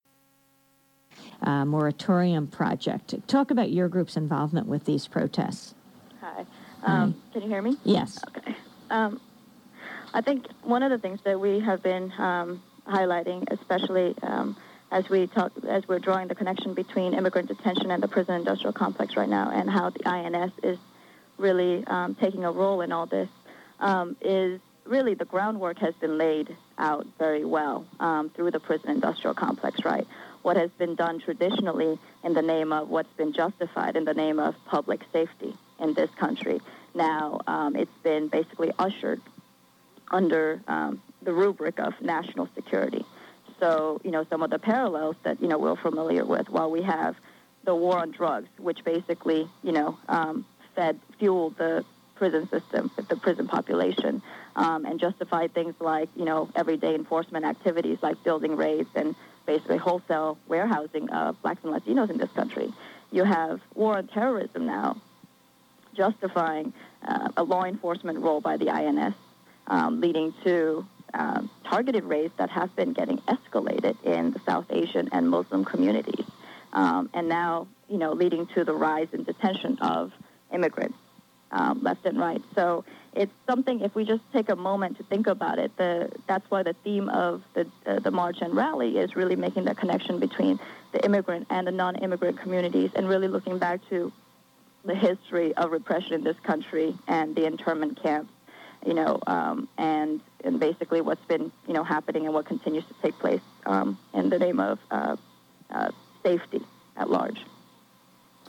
DRUM-PMP-interview-Drawing-connections-between-immigration-and-the-PIC.mp3